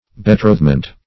Betrothment \Be*troth"ment\, n.